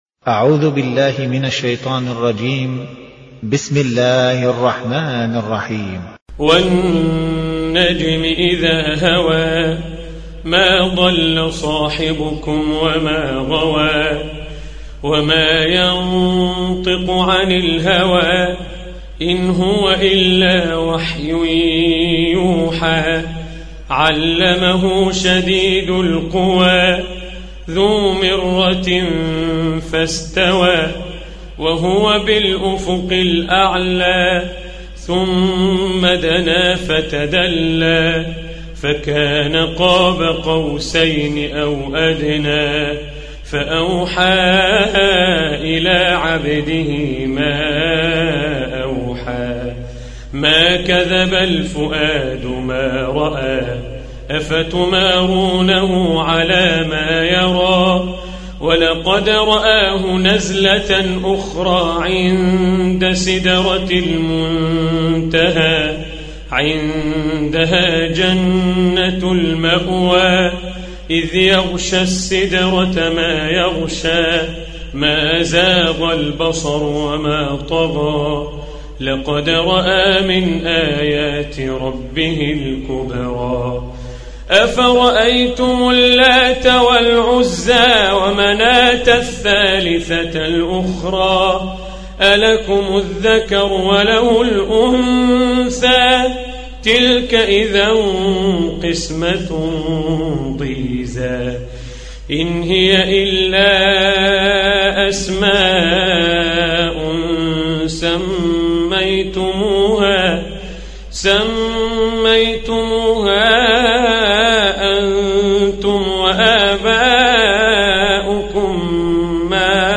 Чтение Корана